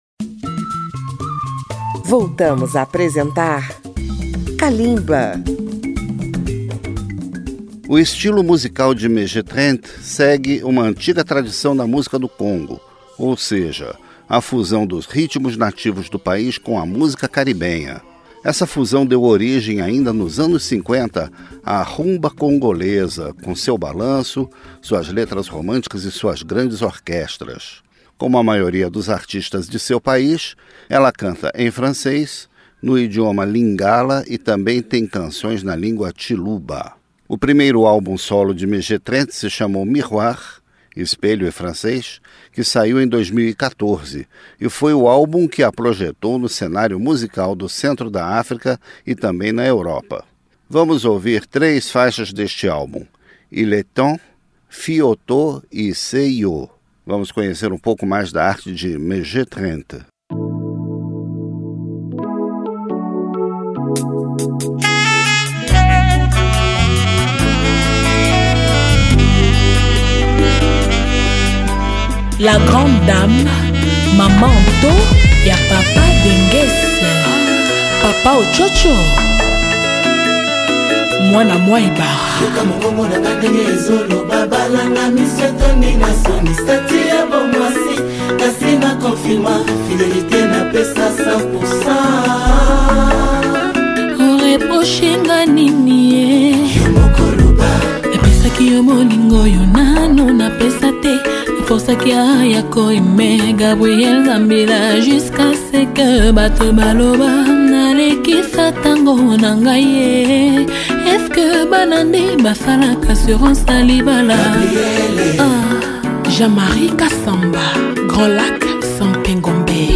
Em março, Mês da Mulher, Kalimba mostra o talento das vozes femininas do continente africano.